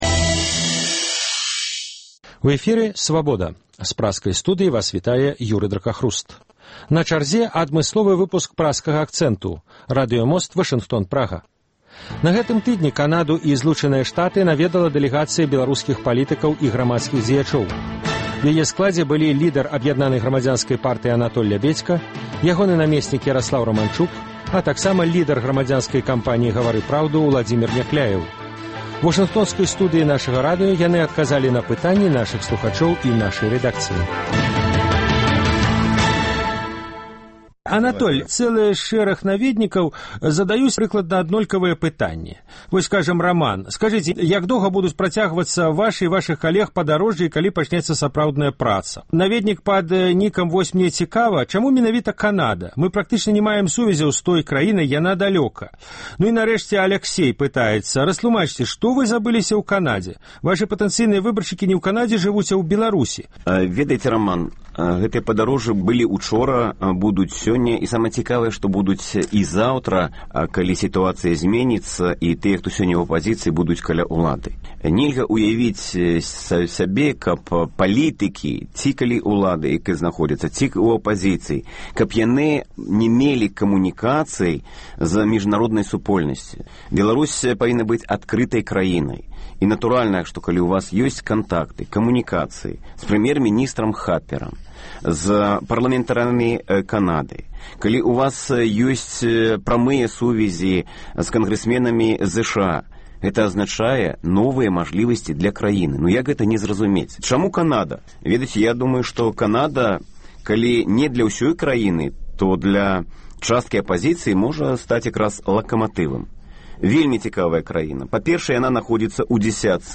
Адмысловы выпуск “Праскага акцэнту” – радыёмост Вашынгтон-Прага. На гэтым тыдні Канаду і ЗША наведвала дэлегацыя беларускіх палітыкаў і грамадзкіх дзеячоў.
У Вашынгтонскай студыі радыё "Свабода" яны адказалі на пытаньні слухачоў.